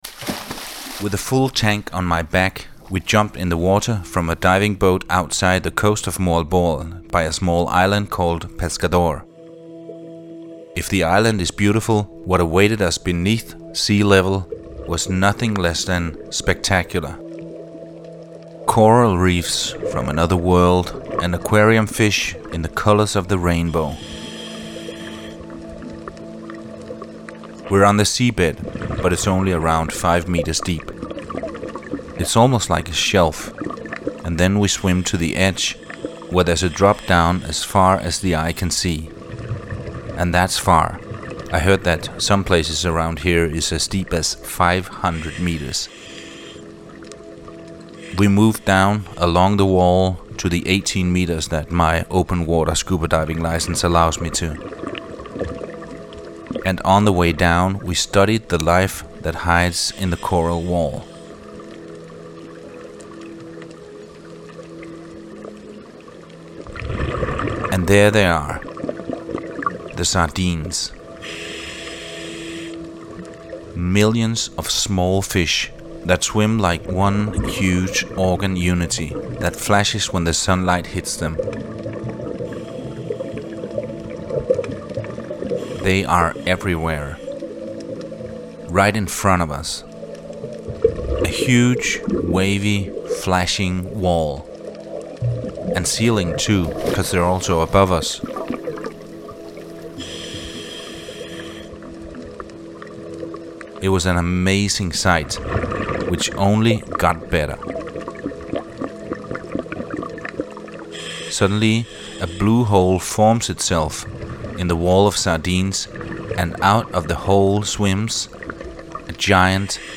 In this episode, I visit two islands in The Philippines: Cebu and Bohol. In the clip you can hear me talk about a unique experience going scuba diving.